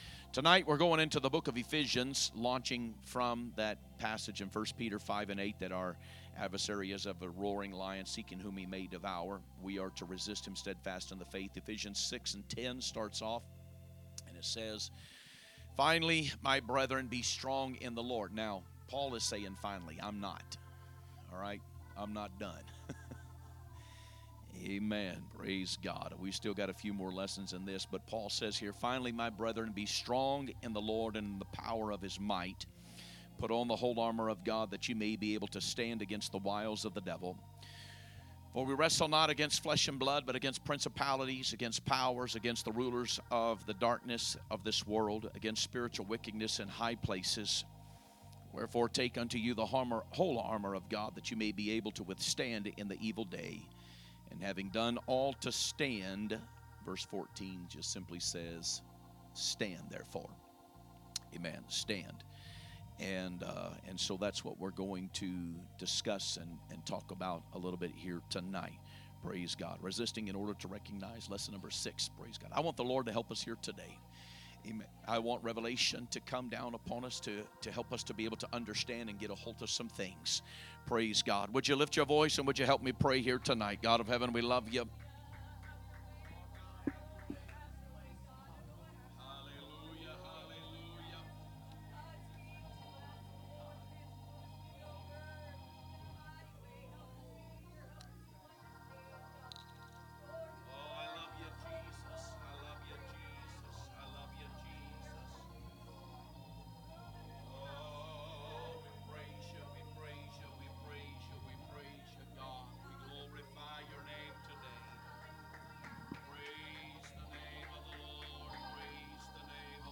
Sunday Evening Message - Lesson 6 - Stand